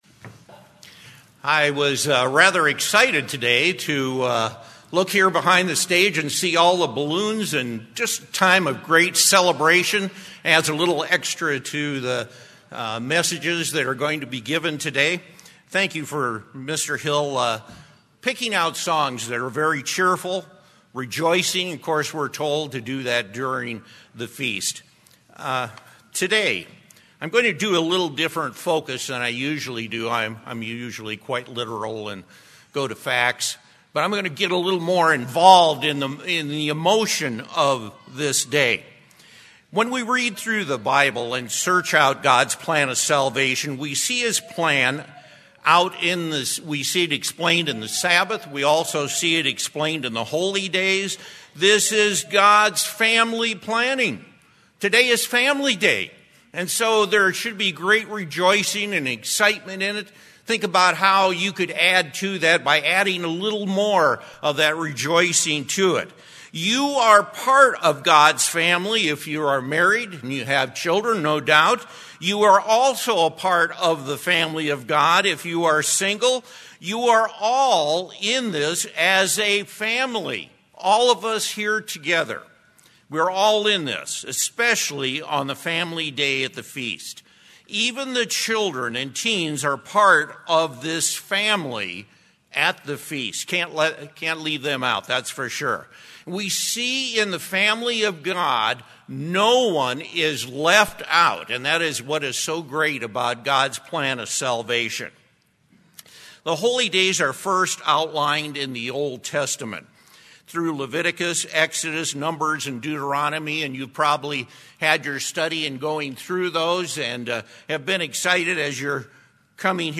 This sermon was given at the Bend-Redmond, Oregon 2018 Feast site.